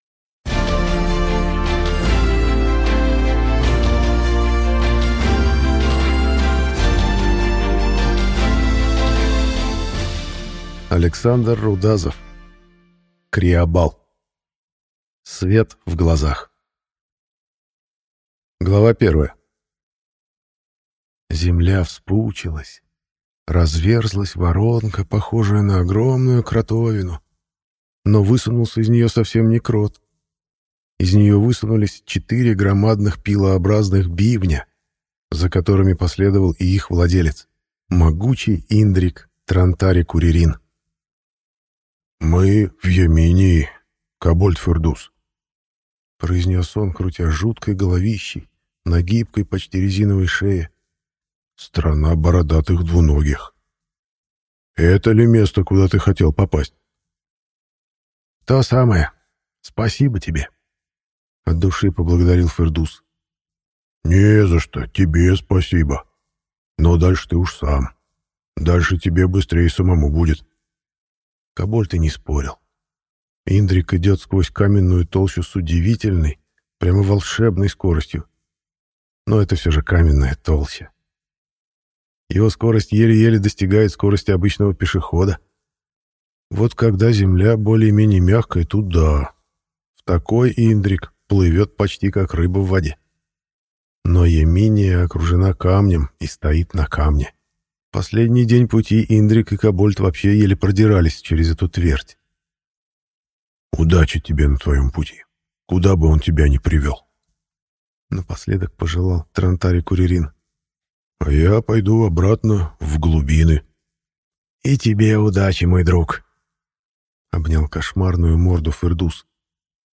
Аудиокнига Криабал. Свет в глазах | Библиотека аудиокниг